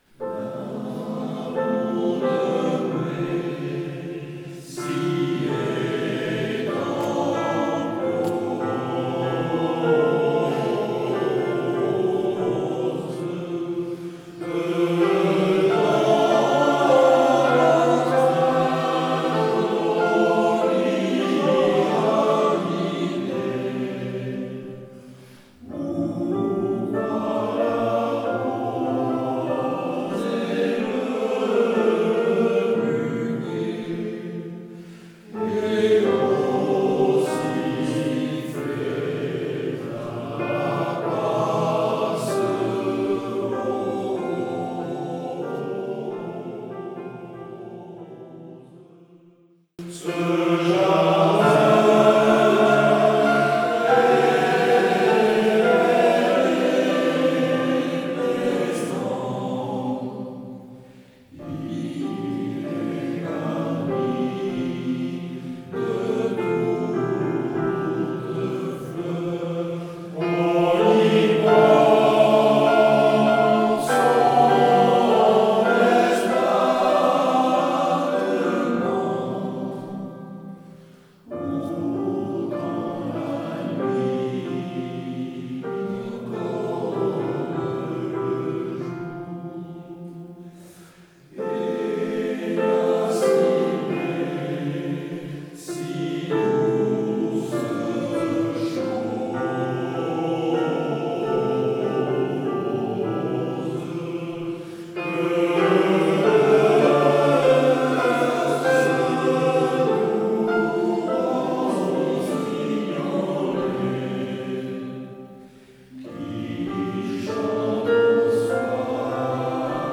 Chants traditionnels